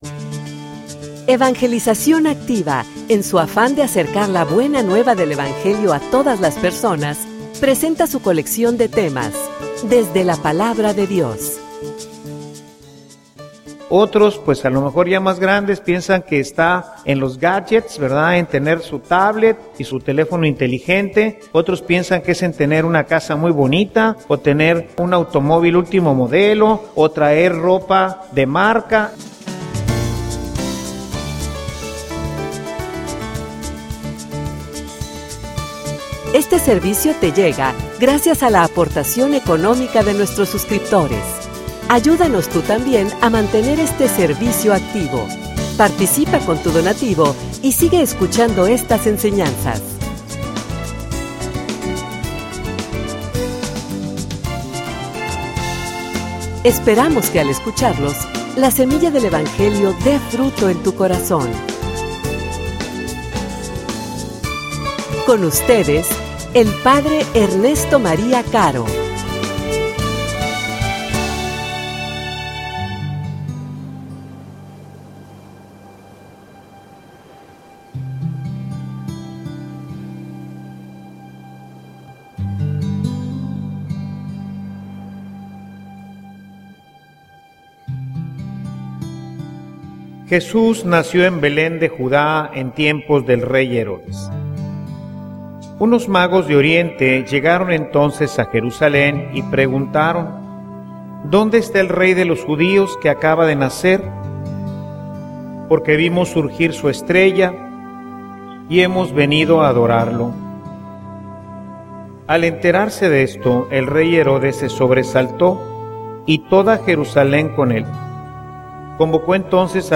homilia_Un_camino_hacia_Dios.mp3